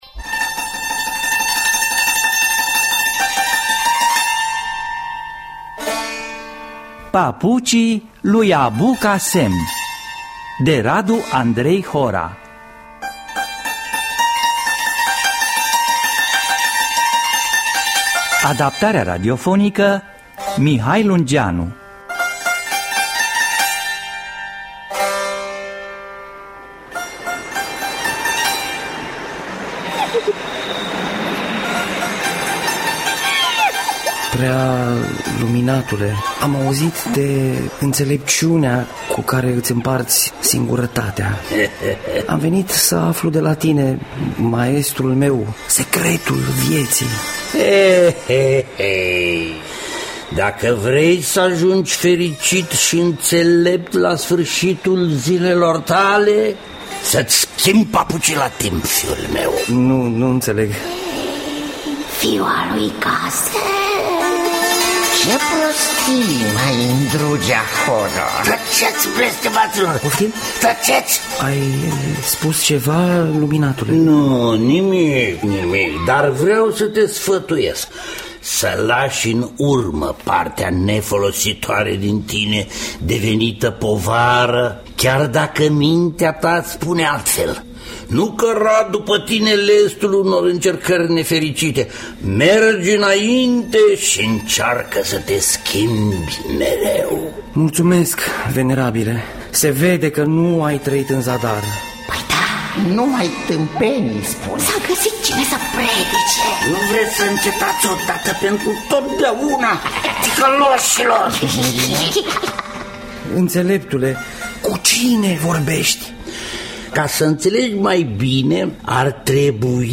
Papucii lui Abu Cassem – Teatru Radiofonic Online